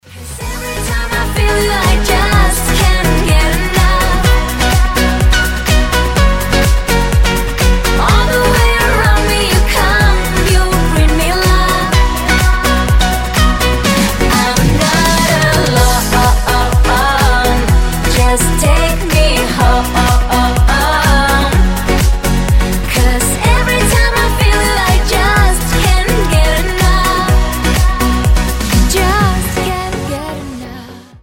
• Качество: 256, Stereo
поп
веселые
dance
танцевальные
happy